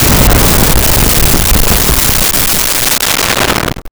Explosion 04
Explosion 04.wav